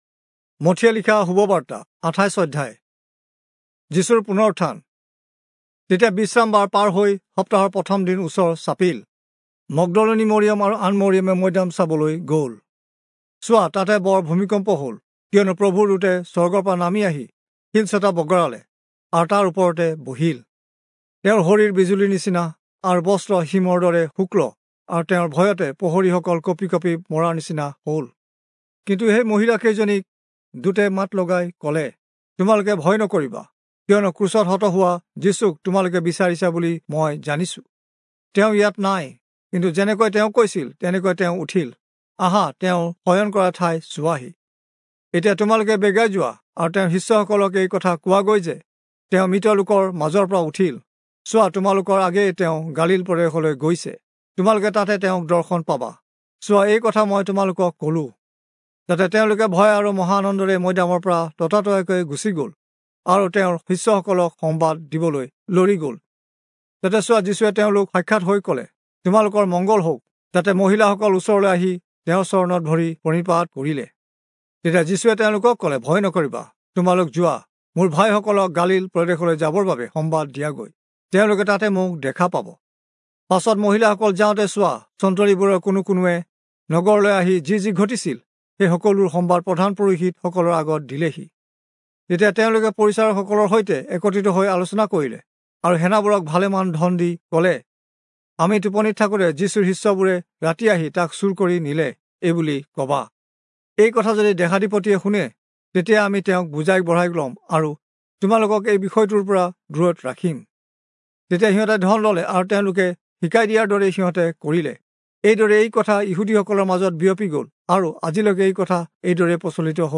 Assamese Audio Bible - Matthew 4 in Kjv bible version